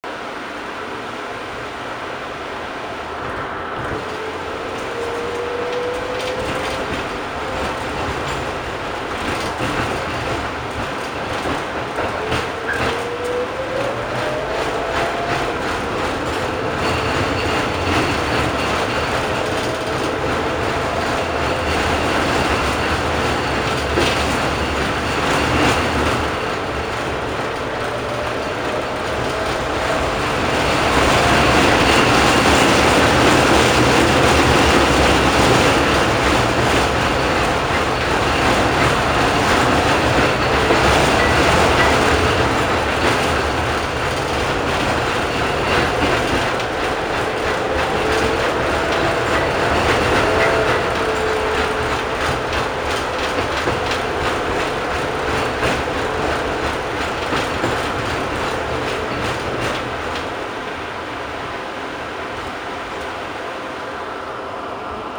На этой странице собраны звуки трамваев: от ритмичного перестука колес по рельсам до характерных звонков и гула моторов.
Старинный трамвай